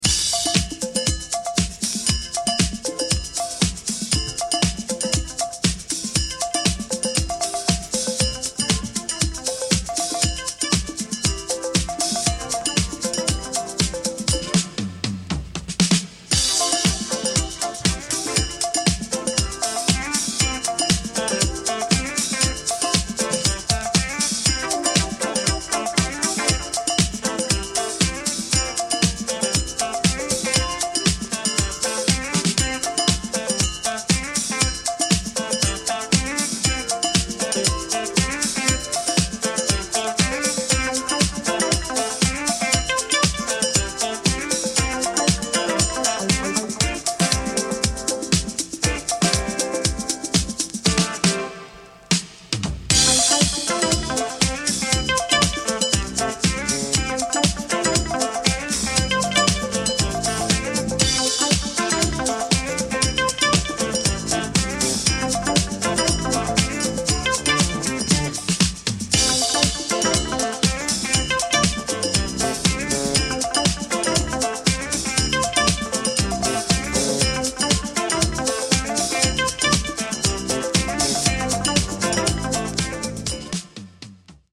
Heavily sampled disco inst.